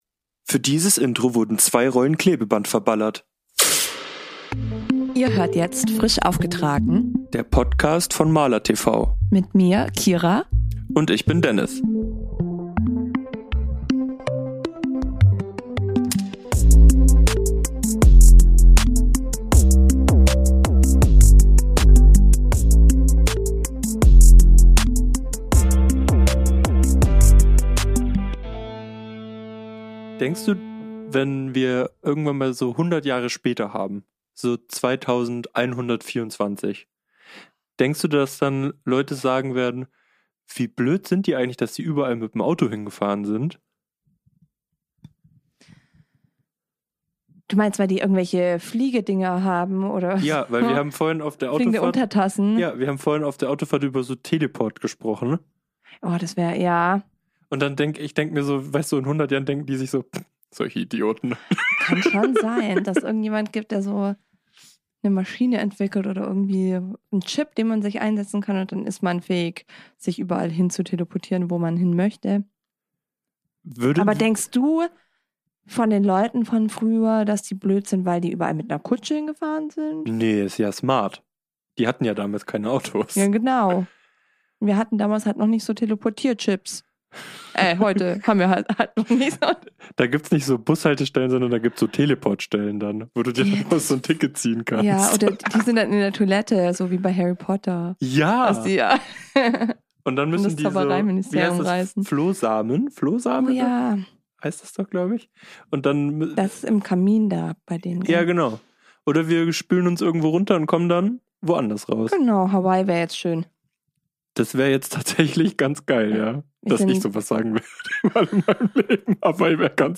Hier hört ihr unsere höchst professionelle Podcastaufzeichnung, nach guten neun Stunden Autofahrt! :D Viel Spaß beim hören!